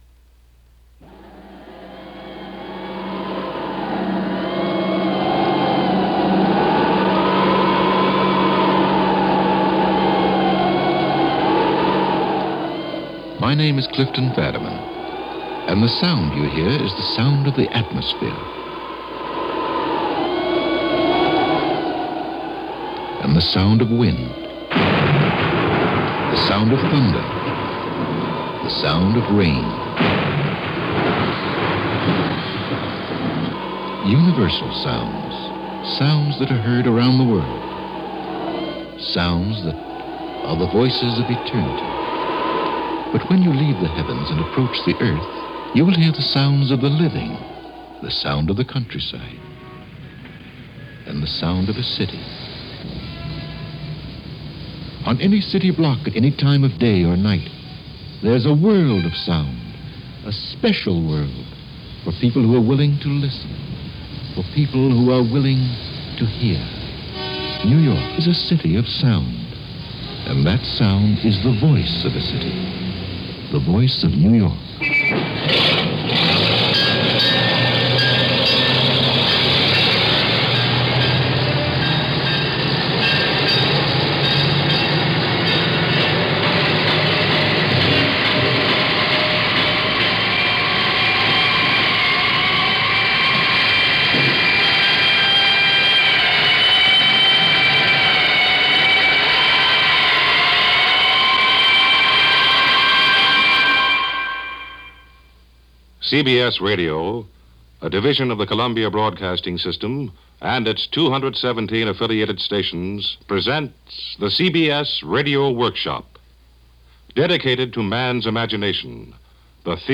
New York in the 1950s – Capturing the sound and soul of a City.
This episode, entitled The Voice Of New York, showcases the talents of Audio artist and Sound designer Tony Schwartz who, armed with a portable tape recorder wandered the streets of New York looking for sounds that would epitomize the New York experience.